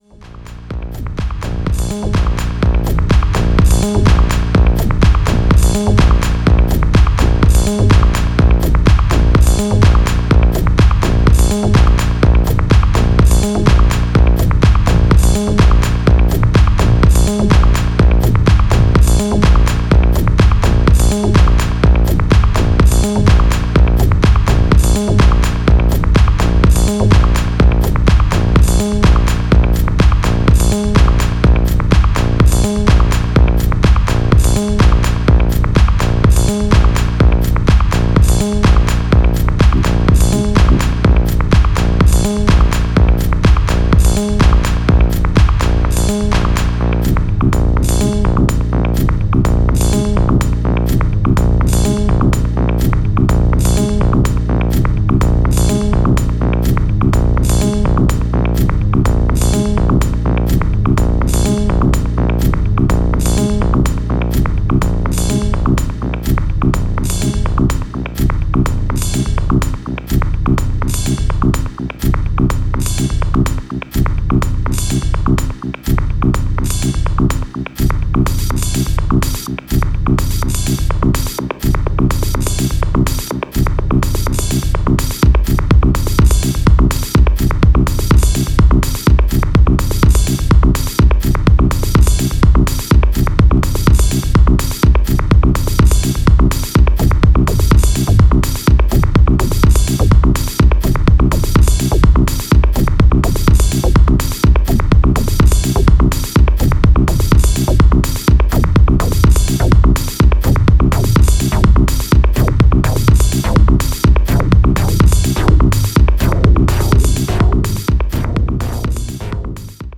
無機質なベースラインの圧の強い存在感が頼もしい